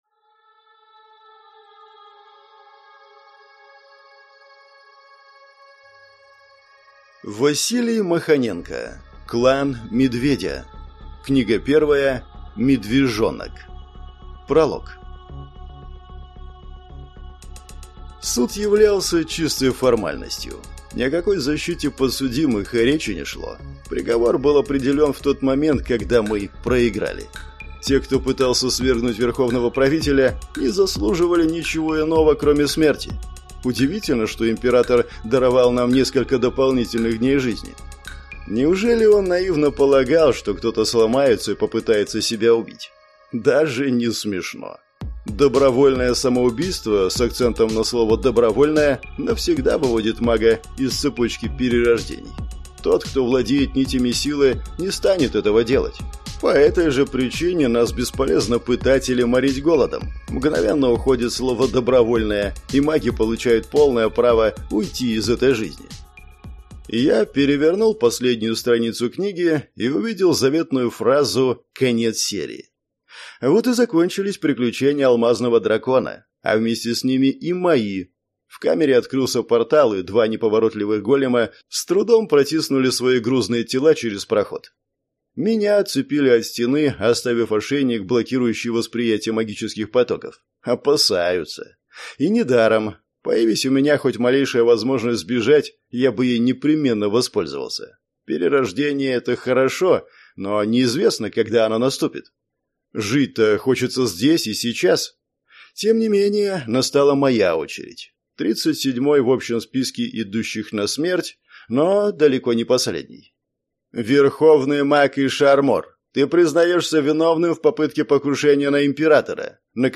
Аудиокнига Клан Медведя. Книга 1. Медвежонок | Библиотека аудиокниг